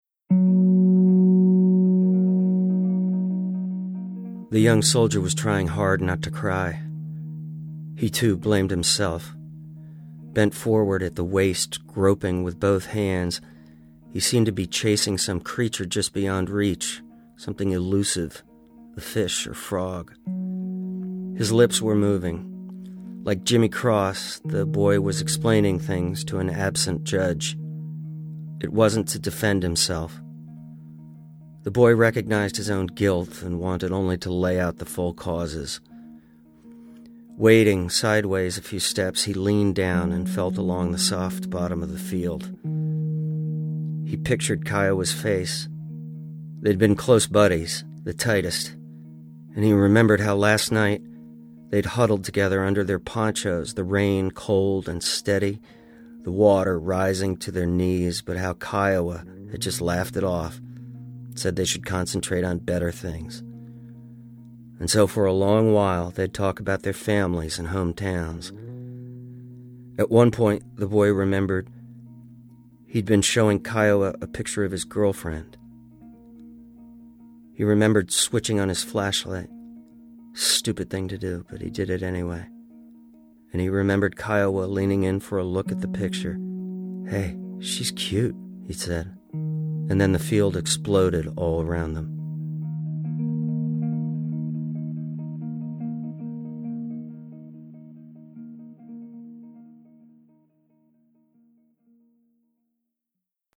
Actor Bradley Whitford reads from the Vietnam War classic, The Things They Carried [1:44]
Bradley Whitford knocks it out of the park with this riveting outtake from our Big Read audio doc about The Things They Carried by Tim O'Brien. In this passage, a soldier grapples with a terrible mistake that cost his dear friend, Kiowa, his life.